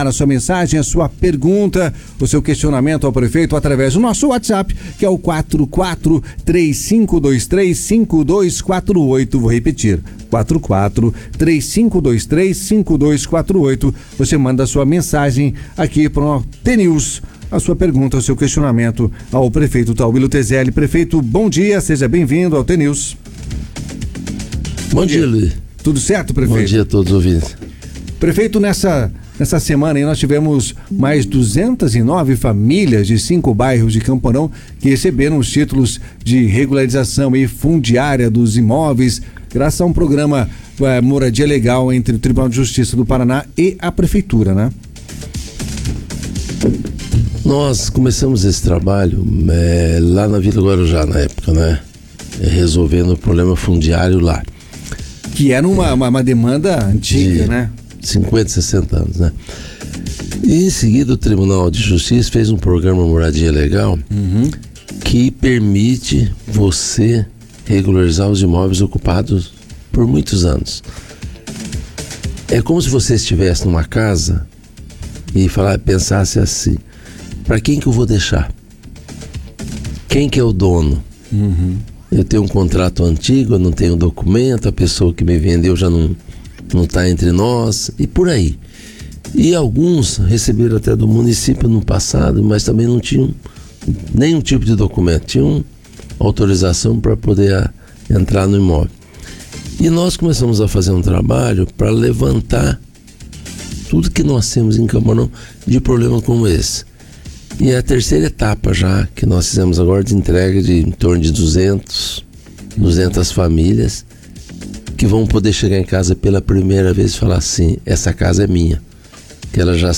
O jornal T News, da Rádio T FM, contou nesta sexta-feira (17) com a participação de Tauillo Tezelli, atual prefeito de Campo Mourão.
Programa Moradia Legal e ações na saúde mourãoense, alguns dos temas tratados no programa. Clique no player abaixo e ouça a íntegra da entrevista.